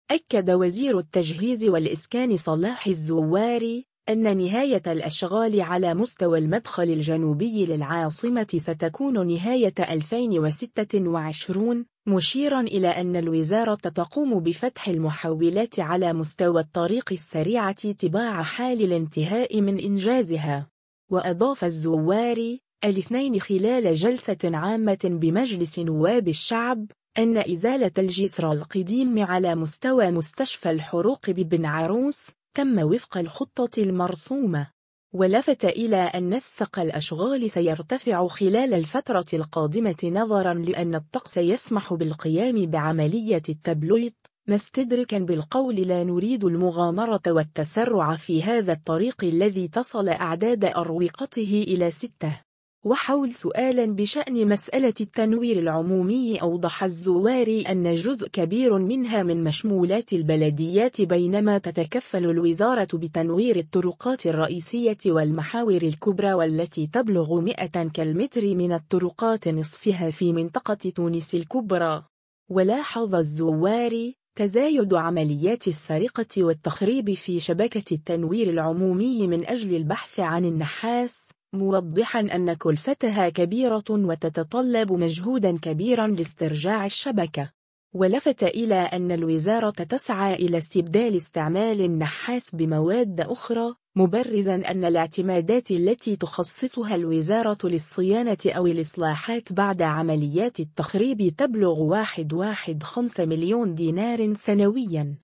و أضاف الزواري،الاثنين خلال جلسة عامة بمجلس نواب الشعب، أن إزالة الجسرالقديم على مستوى مستشفى الحروق ببن عروس ،تم وفق الخطة المرسومة.